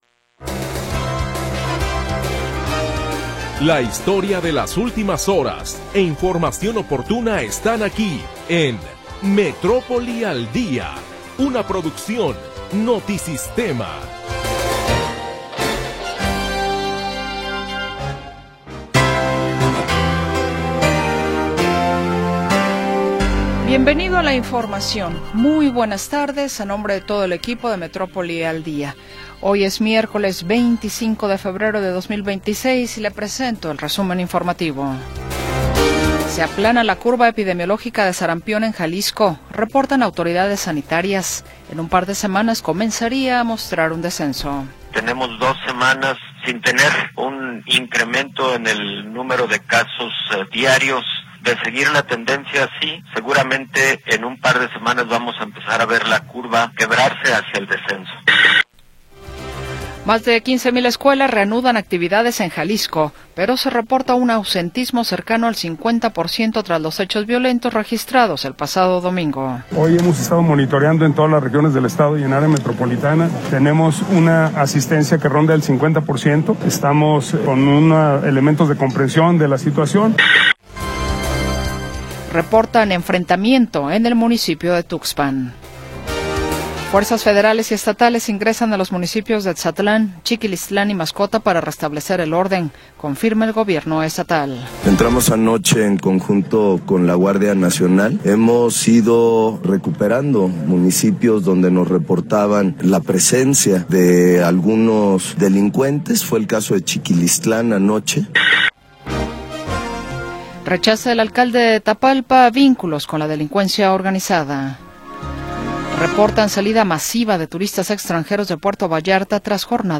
La historia de las últimas horas y la información del momento. Análisis, comentarios y entrevistas